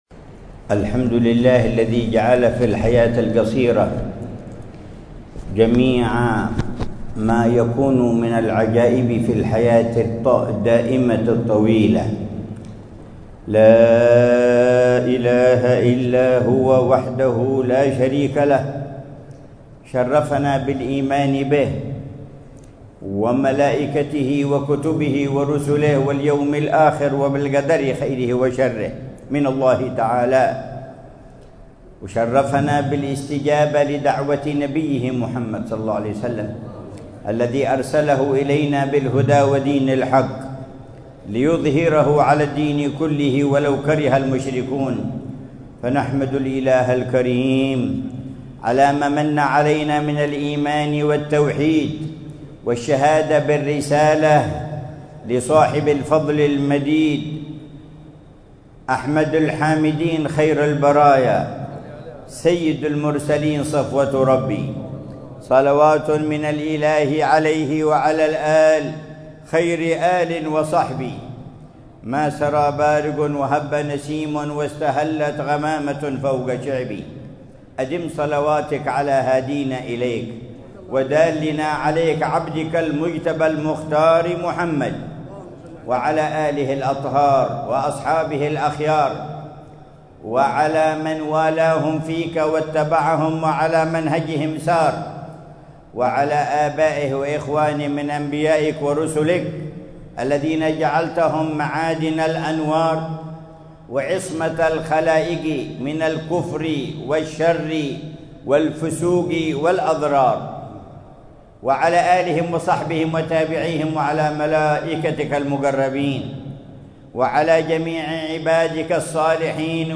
محاضرة في مسجد الإمام عبد الله بن علوي الحداد في مدينة سيئون، ليلة السبت 28 جمادى الأولى 1446هـ بعنوان: